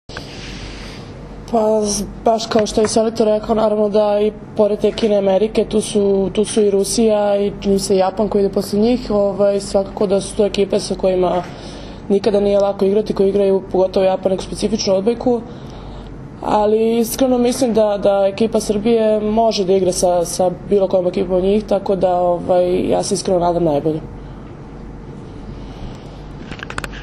Tim povodom danas je u beogradskom hotelu „M“ održana konferencija za novinare, kojoj su prisustvovali Zoran Terzić, Maja Ognjenović, Jelena Nikolić i Milena Rašić.
IZJAVA MILENE RAŠIĆ